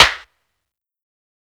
SNARE_INJECTION.wav